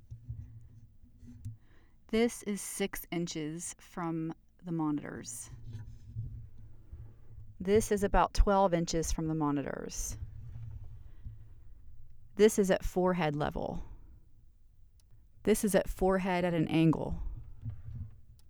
Ok, so this was holding it, nothing special no pop filter etc. I’m going to guess that forehead placement is best by the looks of the waves on the screens?
Your test (1.56 MiB) confirms that having the mic close to monitor screens causing the boxy problem.
“six inches from the monitors” is definitely unusable.
“forehead at an angle” is the best sounding of the four takes, IMO.
There’s no buzzing on your mic test “audacity sample.wav (1.56 MiB)”.